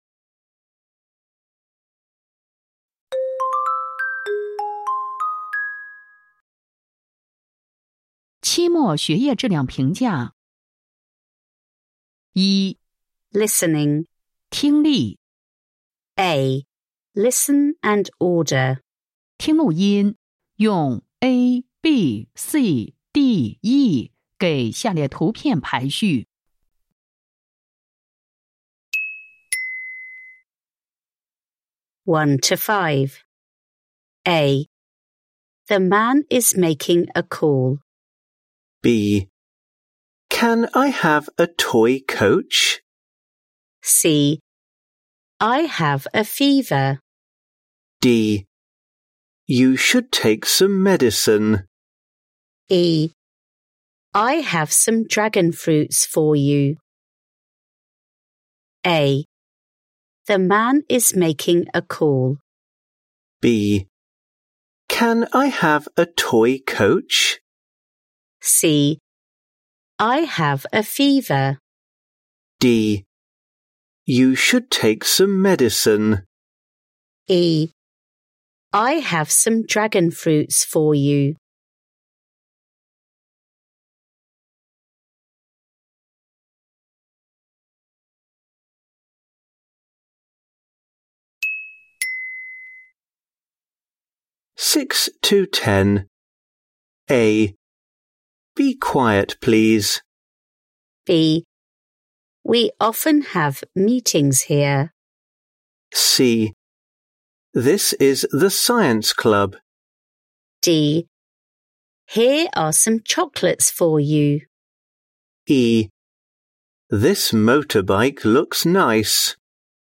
小学学习质量监测英语五年级下册听力材料录音 - 天津教育出版社